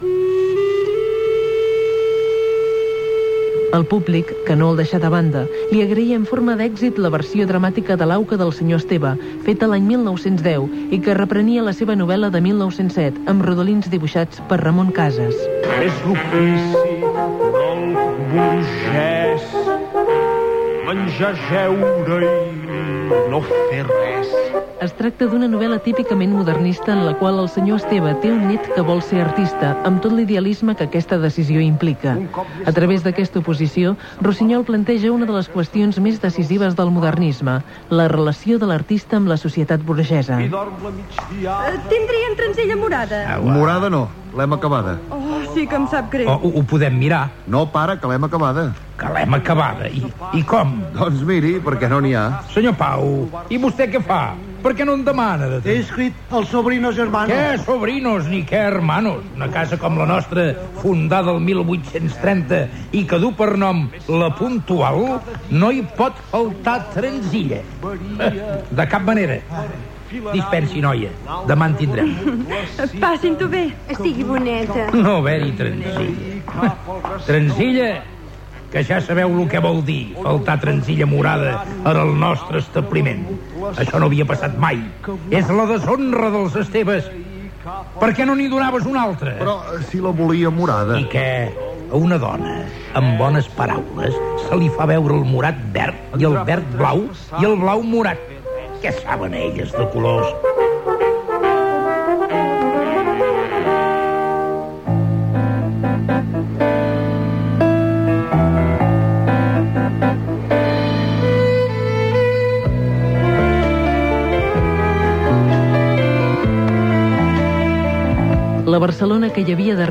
Ficció
FM